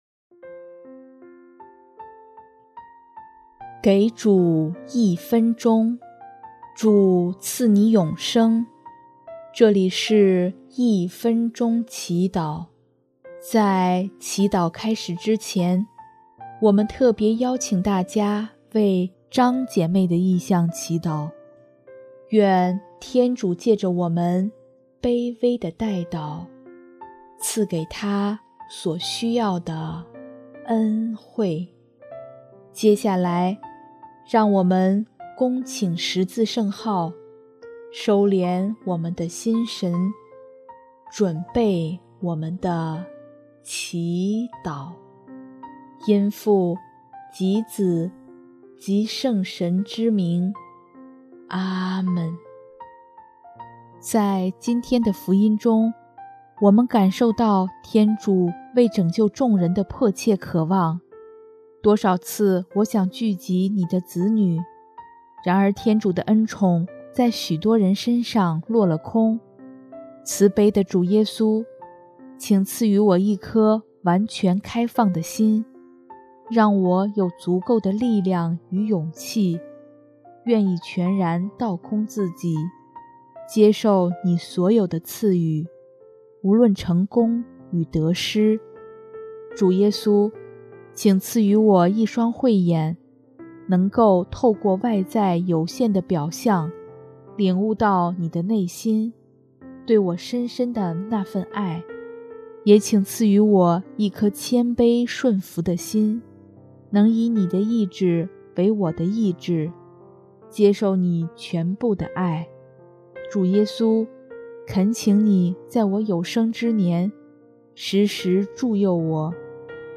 音乐： 第三届华语圣歌大赛参赛歌曲《你的手》